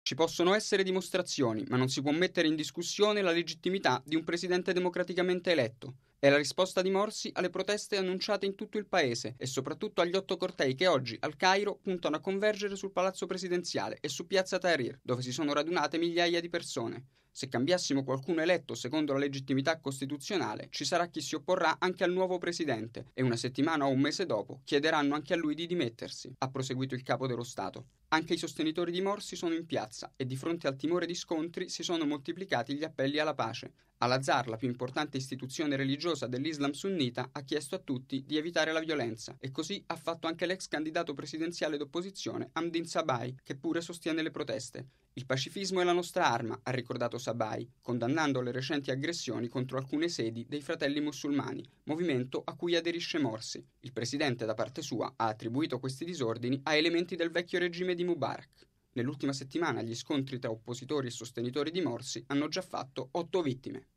Radiogiornale del 30/06/2013 - Radio Vaticana